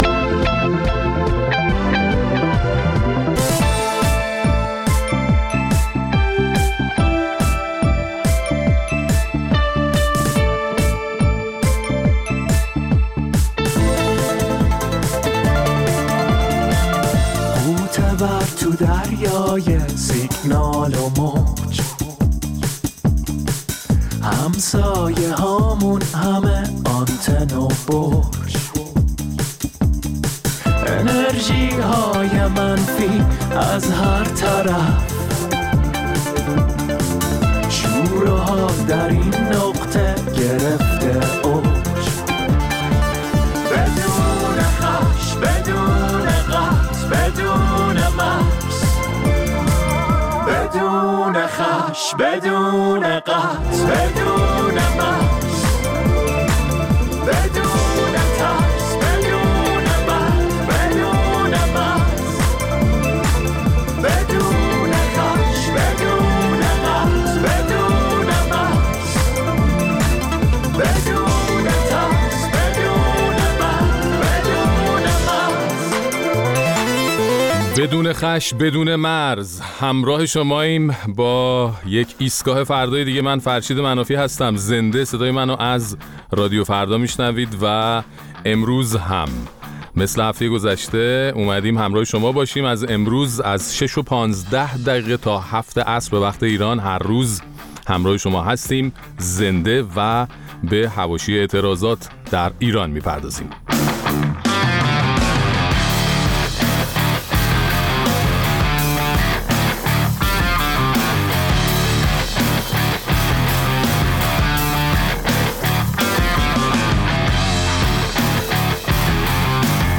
در این ایستگاه فردای ویژه، با توجه به ادامه اعتراضات سراسری در ایران، به حواشی و متن این اعتراضات می‌پردازیم و نظرات و مشاهدات شنوندگان برنامه را هم در این باره می‌شنویم.